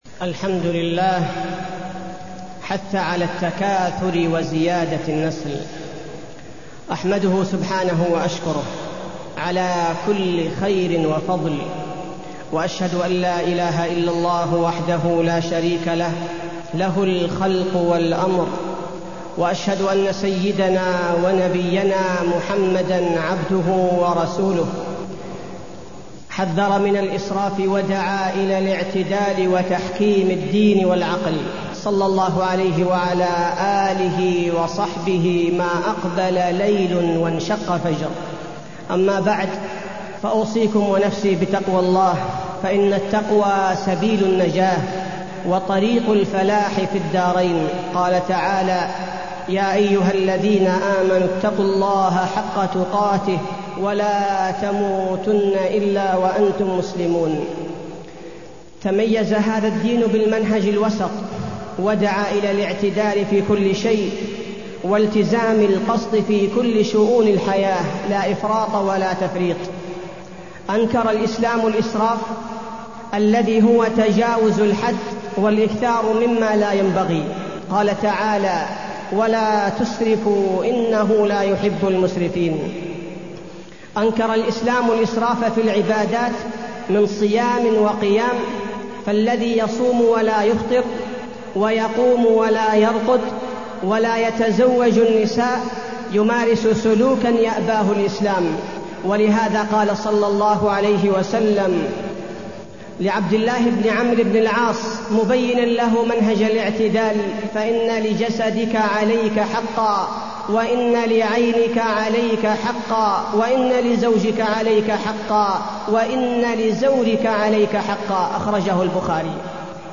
تاريخ النشر ٢٨ ربيع الأول ١٤٢١ هـ المكان: المسجد النبوي الشيخ: فضيلة الشيخ عبدالباري الثبيتي فضيلة الشيخ عبدالباري الثبيتي الإسراف والتبذير